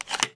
spas12_insertshell.wav